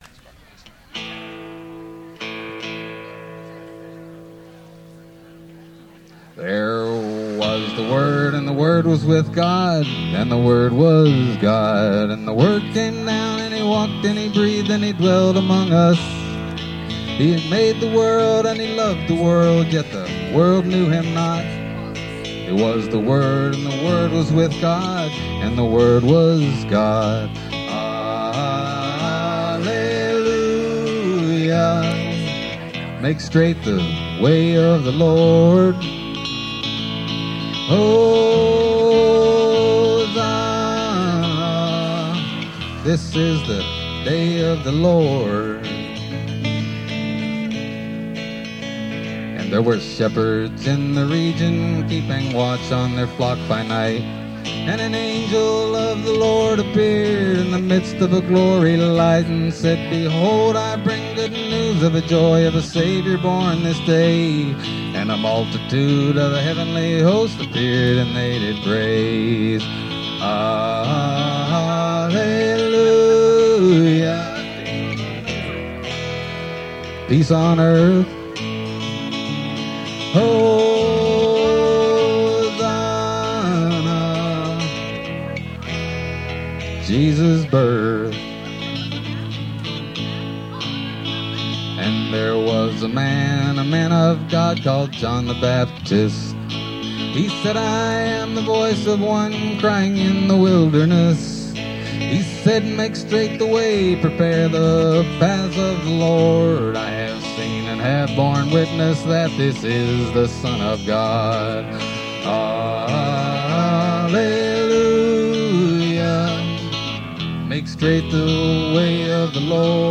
live at the BAM Picnic